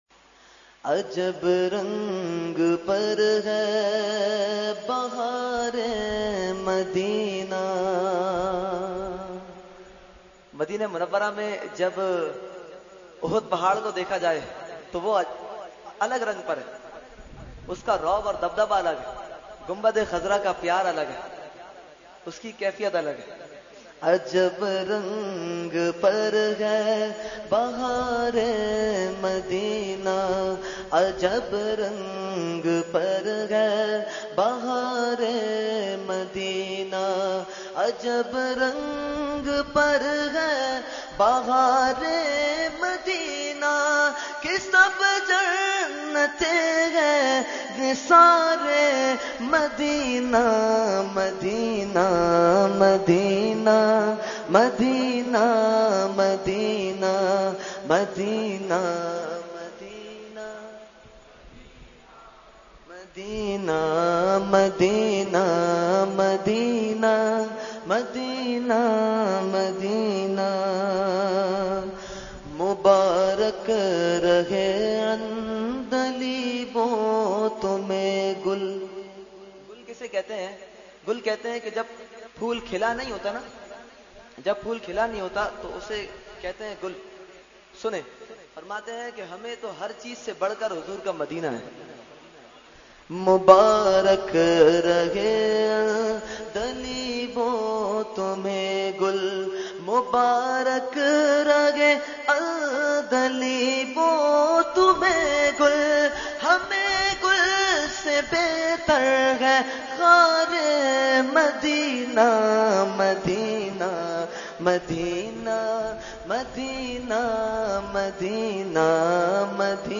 Category : Naat | Language : UrduEvent : Khatmul Quran 2018